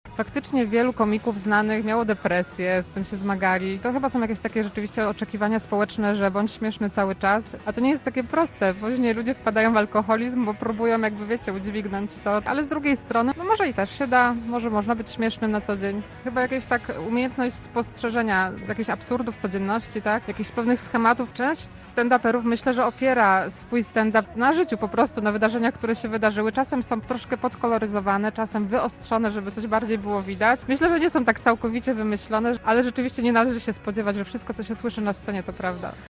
mówiła goszcząca w Wakacyjnym Studio Radia Zielona Góra